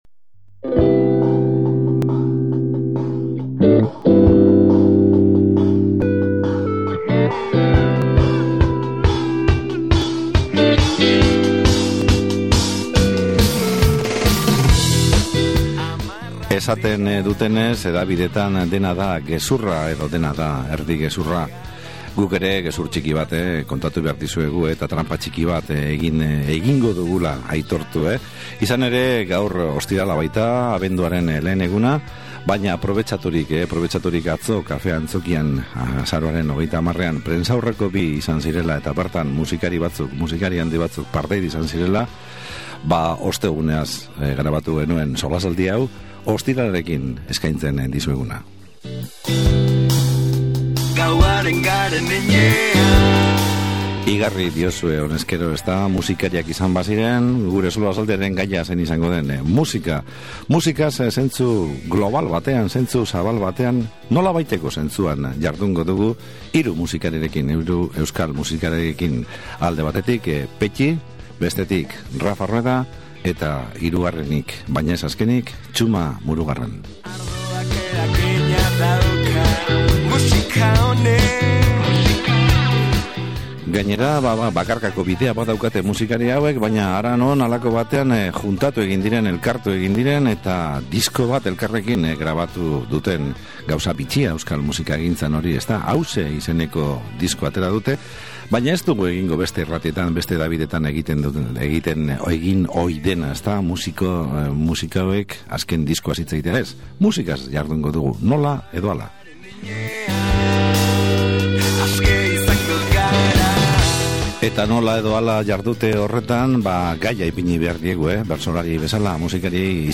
SOLASALDIA
Hauxe izenpean hiru musikariok elkarrekin grabatu duten diskoaren aurkezpena Bilboko Kafe Antzokian izan zutela eta, hirurak gure estudioetara ekarri eta era guztiz lasaian ordu erdiz beraiekin solasten aritzeko probetxatu genuen. Ohiko moldeetatik ihesi, solasaldi informala nahi genuen eta halakoxea suertatu zen.